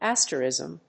音節as・ter・ism 発音記号・読み方
/ˈæstərìzm(米国英語), ˈastəɹɪzəm(英国英語)/